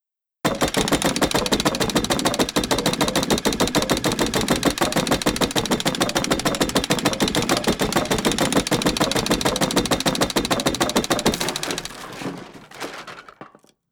Jackhammer Sound Effect Free Download
Jackhammer